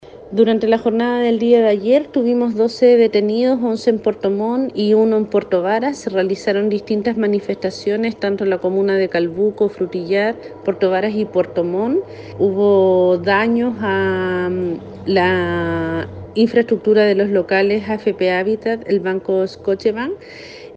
La Gobernadora de Llanquihue, Leticia Oyarce, precisó que en la jornada de ayer hubo 12 detenidos: 11 en Puerto Montt  y 1 en Puerto Varas, en la capital regional se presentaron daños a las oficinas de AFP Habitat y Banco Scotiabank, incluyendo saqueo de mobiliario para luego quemar en barricadas.